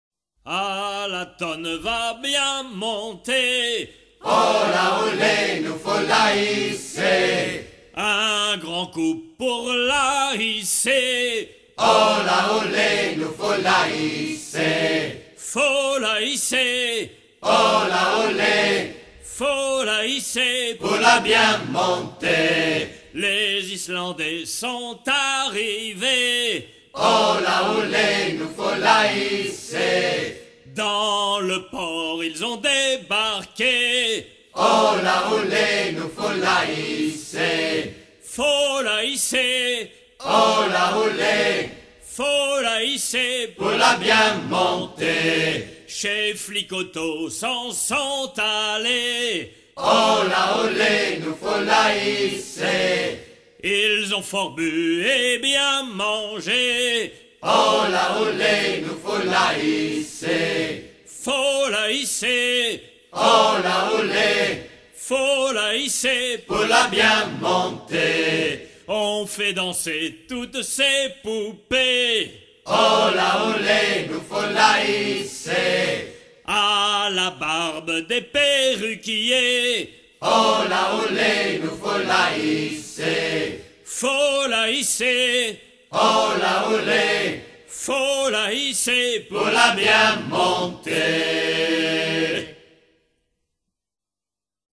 Fonction d'après l'analyste gestuel : à hisser main sur main ;
Genre laisse